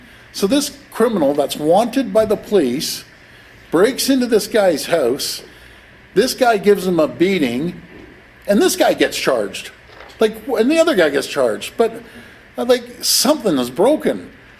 The incident has drawn sharp commentary from Ontario Premier Doug Ford, who weighed in on the matter earlier today. Speaking passionately, Ford expressed frustration with a justice system he believes fails to prioritize victims.